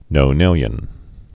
(nō-nĭlyən)